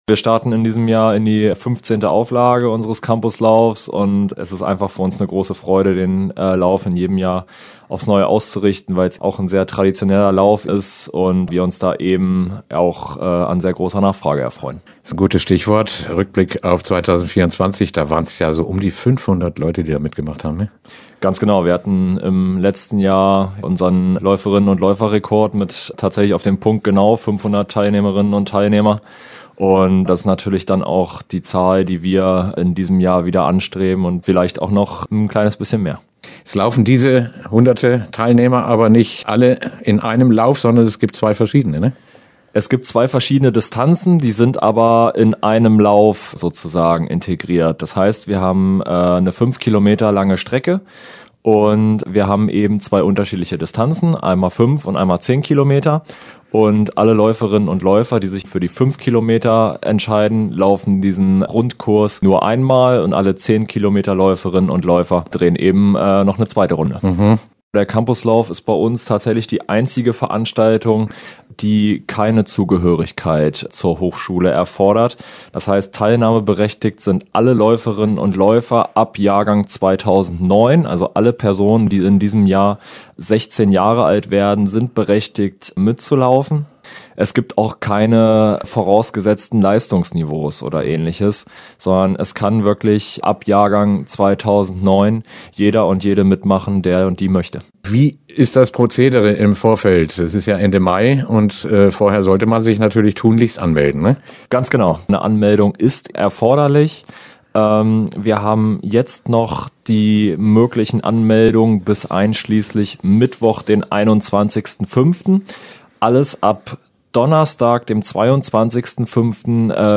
Interview-TU-Campuslauf-2025.mp3